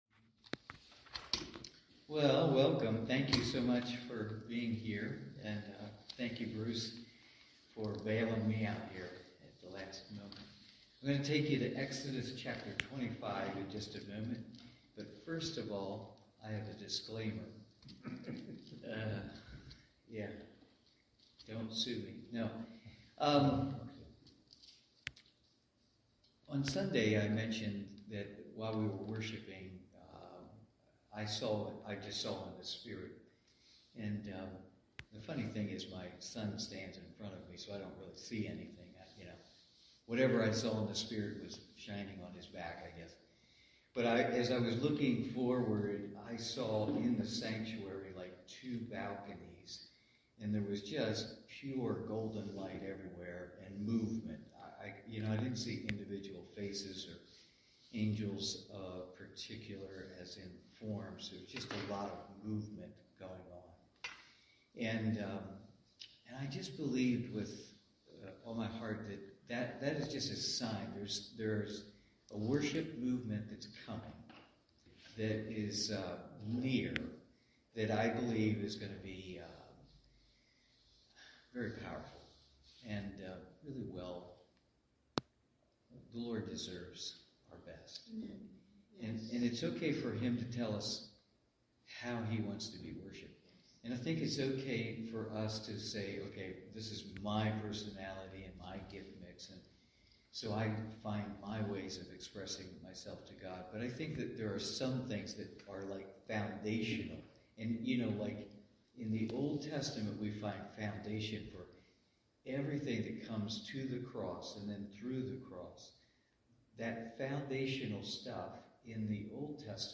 Cornerstone Fellowship Wednesday evening Bible study.